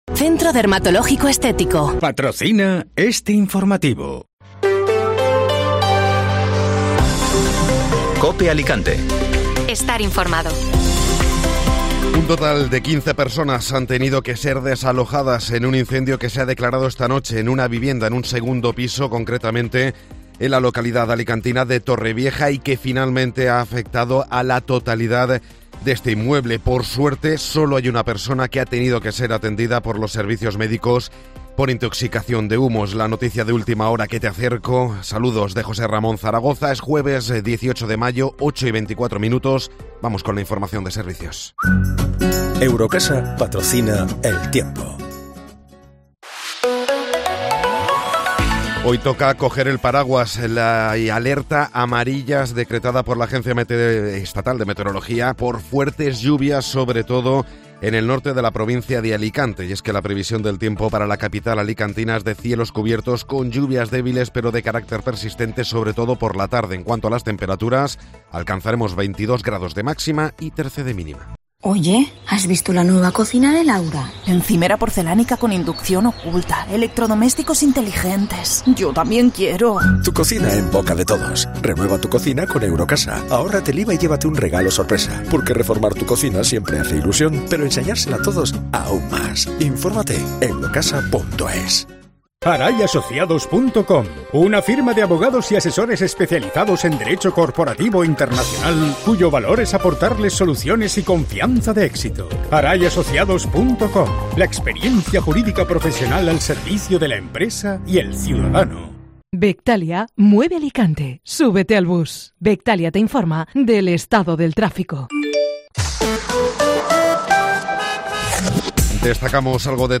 Informativo Matinal (Jueves 18 de Mayo)